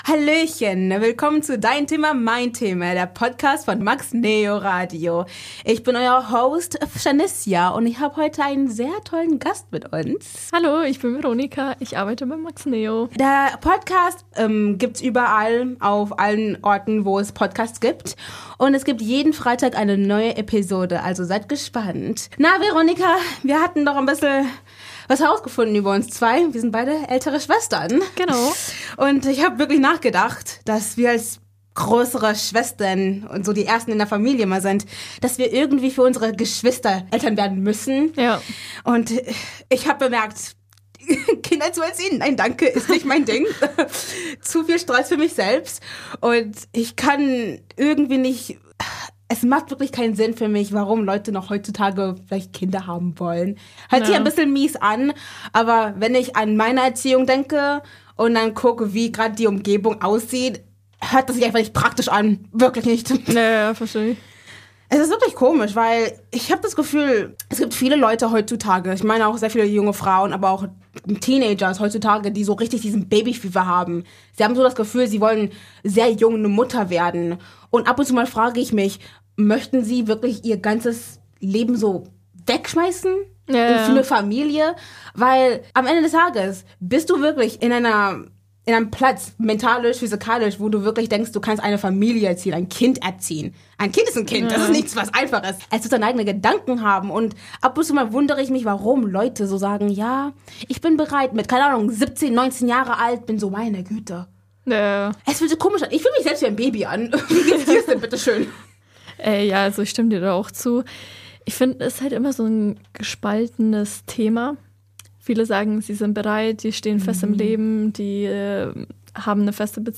unterhalten sich über den gesellschaftlichen Druck, als Frau Kinder bekommen zu müssen. Außerdem geht es um sogenannte Micro-Hobbys.